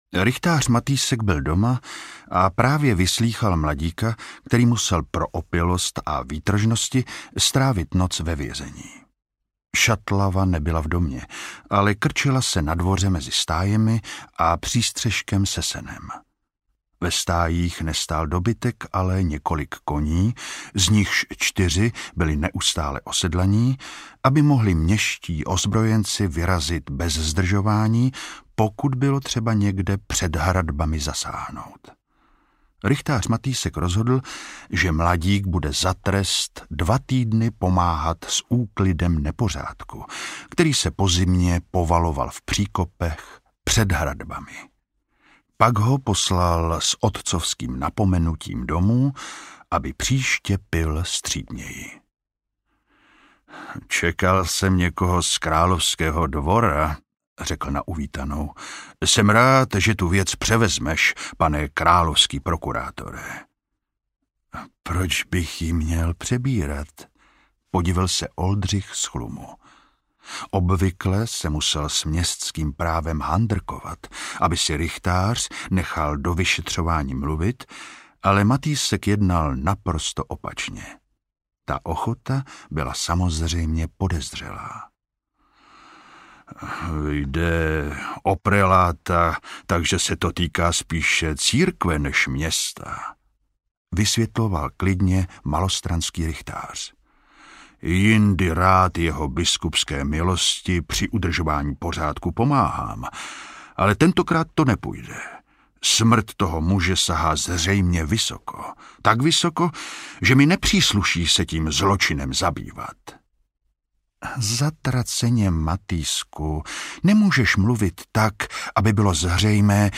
Královražda na Křivoklátě audiokniha
Ukázka z knihy